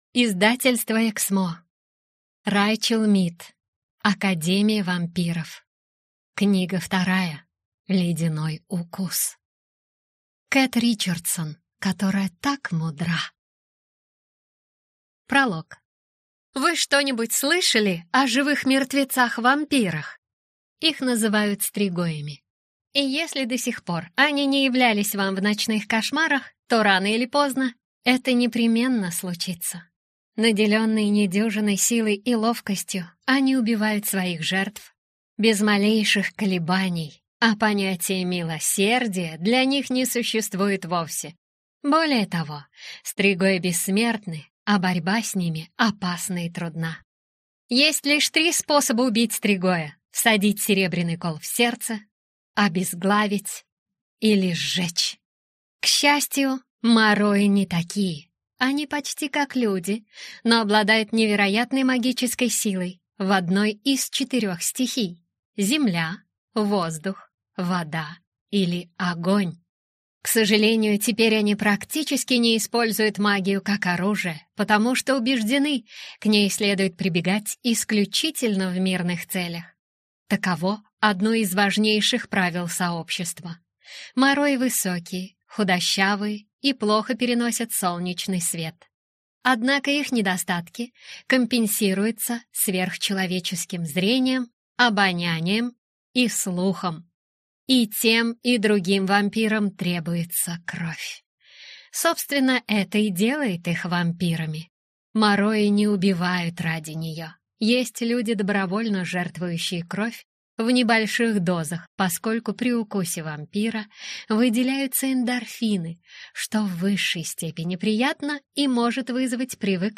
Аудиокнига Ледяной укус - купить, скачать и слушать онлайн | КнигоПоиск